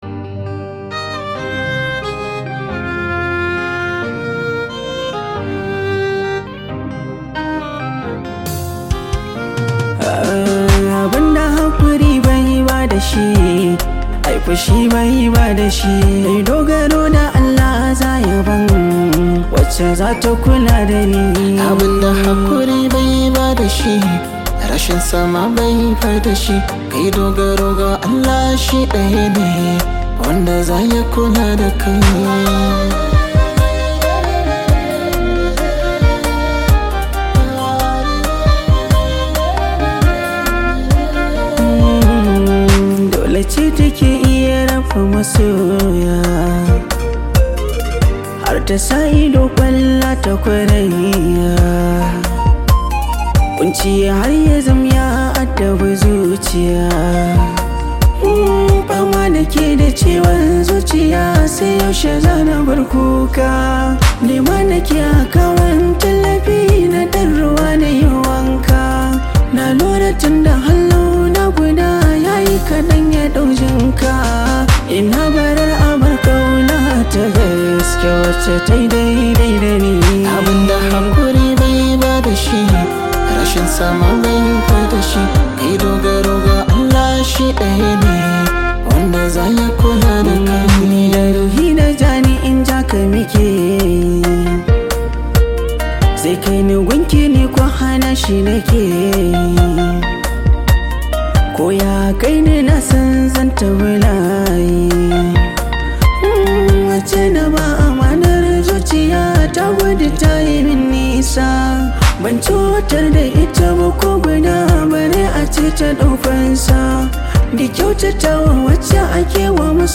Category: Hausa Songs
a romantic song for lovers.